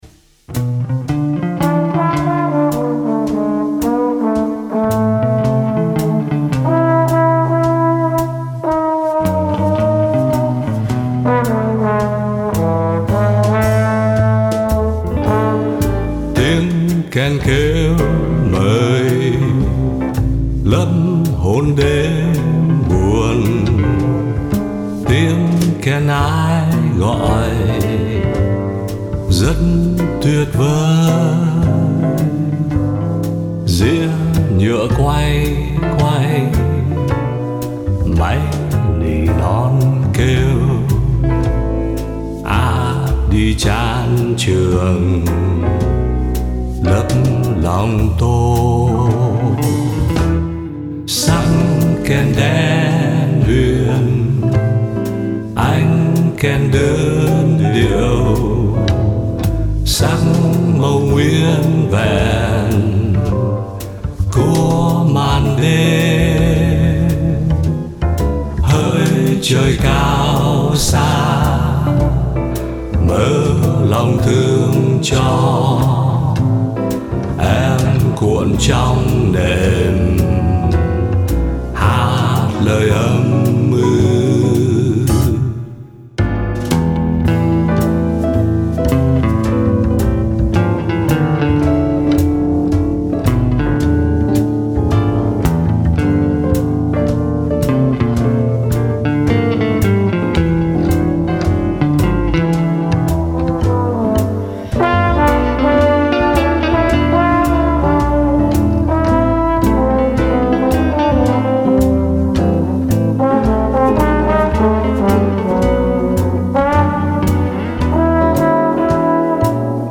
một bài nhạc jazz nhẹ nhàng mà tôi cũng rất thích